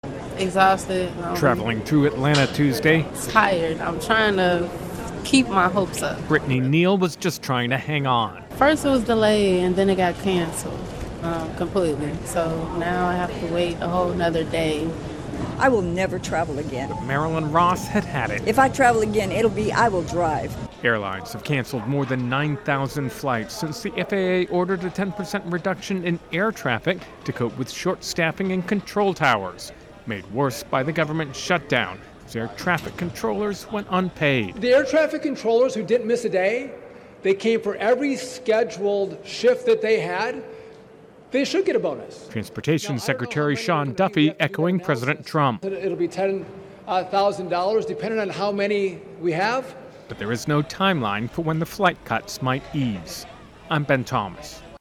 ((opens with actuality))